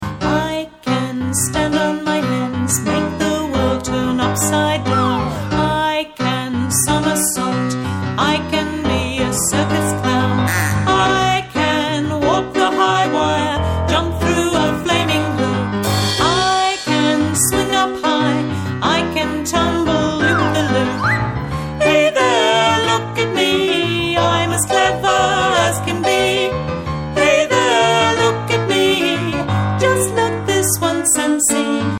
piano accompaniments